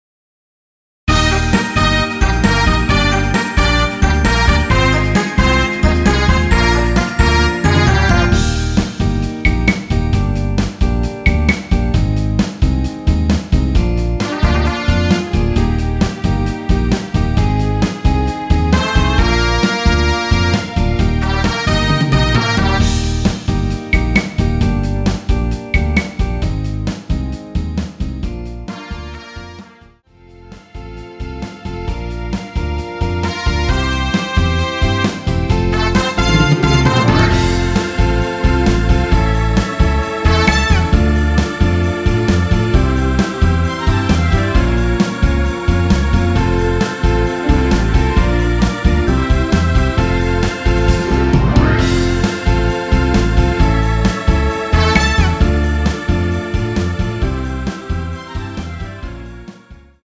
원키에서(-2)내린 MR입니다.
Eb
앞부분30초, 뒷부분30초씩 편집해서 올려 드리고 있습니다.
중간에 음이 끈어지고 다시 나오는 이유는